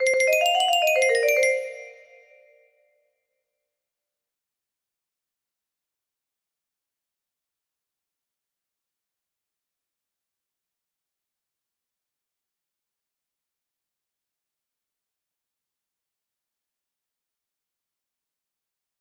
SUSFUCKER music box melody